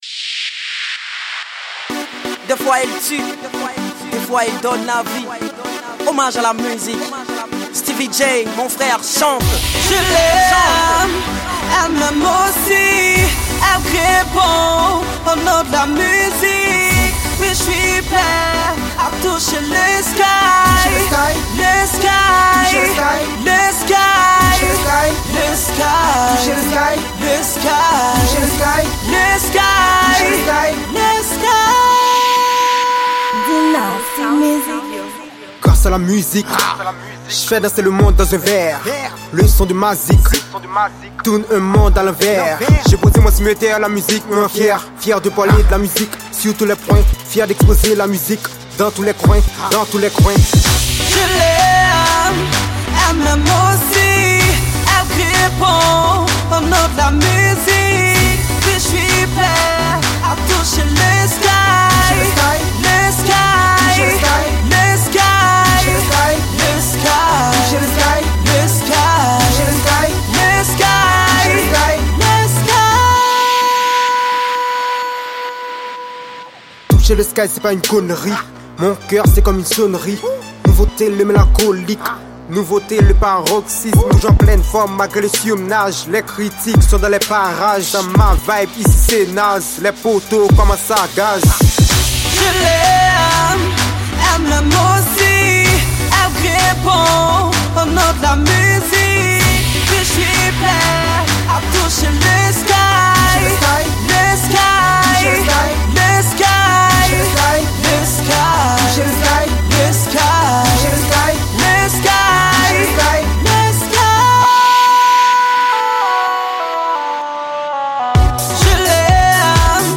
Genre: HOUSE.